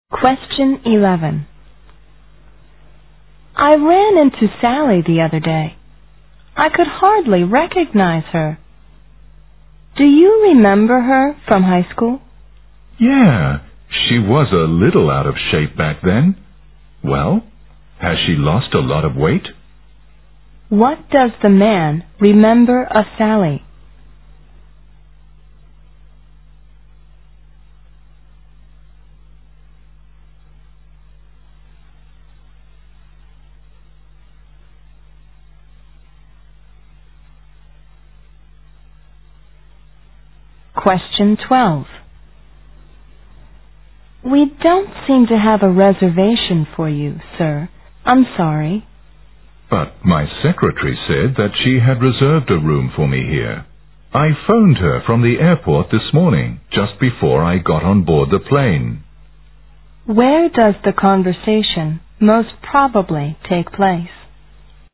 在线英语听力室052的听力文件下载,英语四级听力-短对话-在线英语听力室